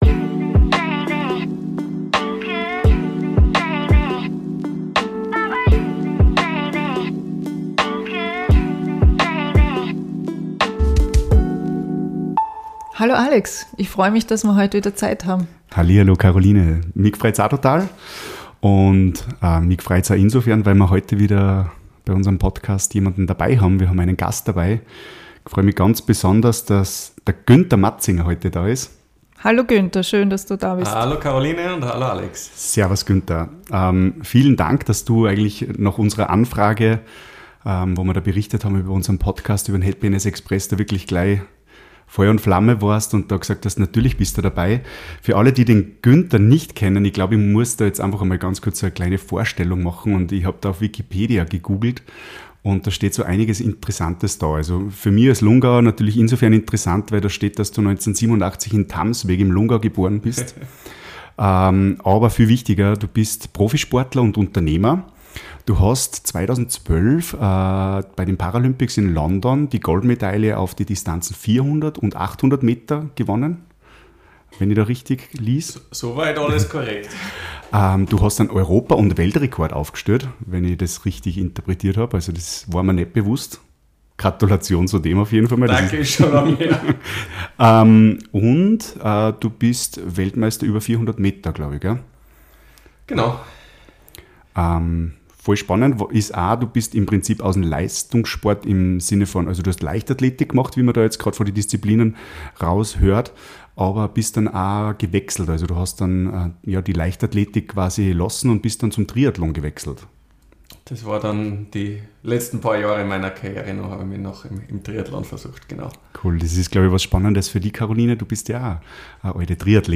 Interview mit Günther Matzinger Part 1 ~ Happiness Express - Der Jugendpodcast zum Thema Mental Health Podcast